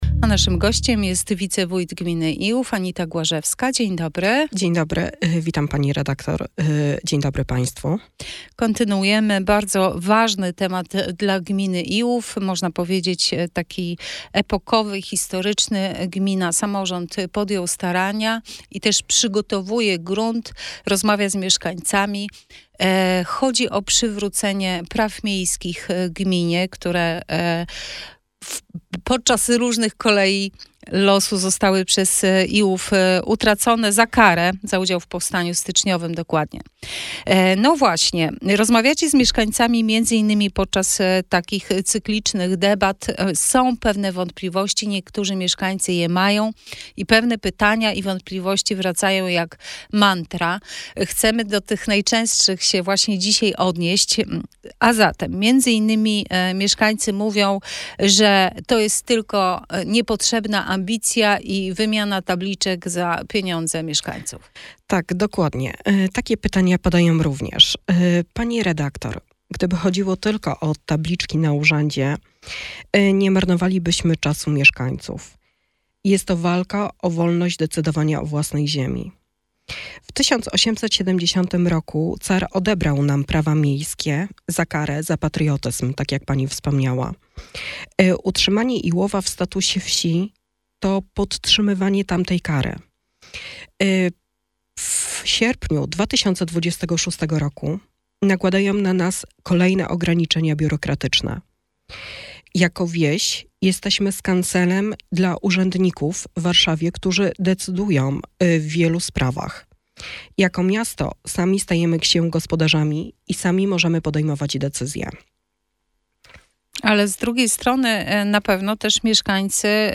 Wywiad z Anitą Głażewską, Zastępcą Wójta Gminy Iłów w Radio Sochaczew - Najnowsze - Gmina Iłów